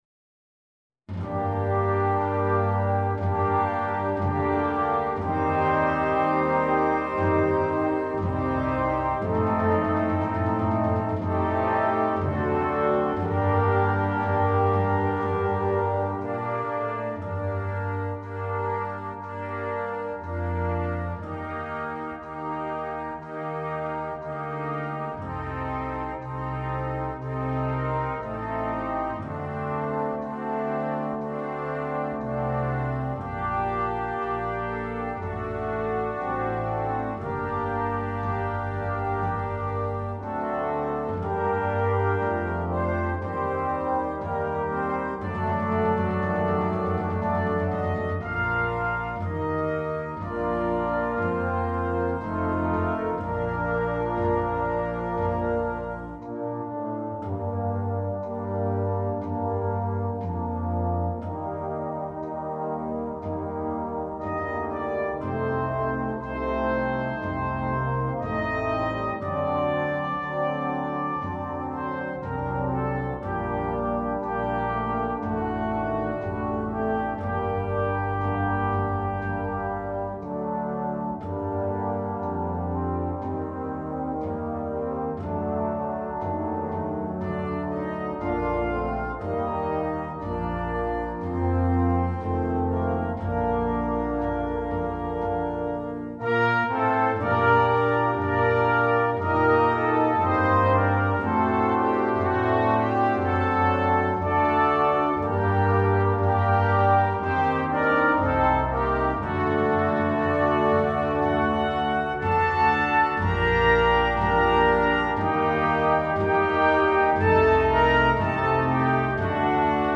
2. Brass Band
Full Band
without solo instrument
Anthem